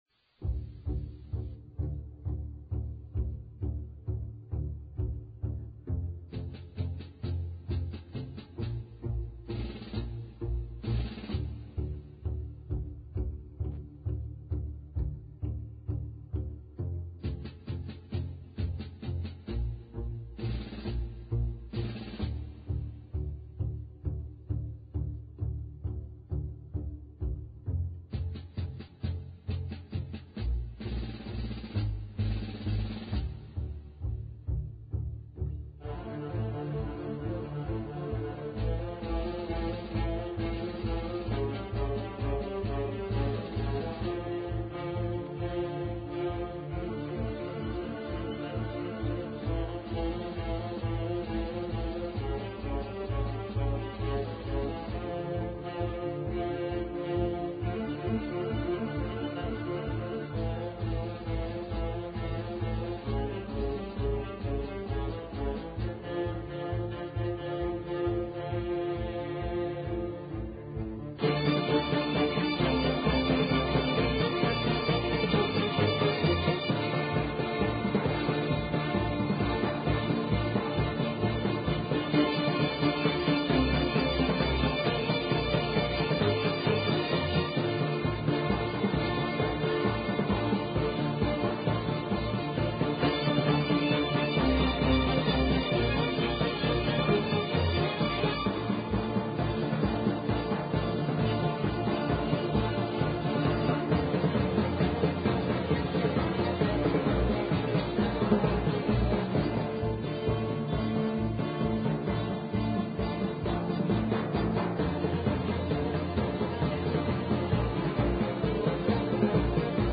a freight train passes by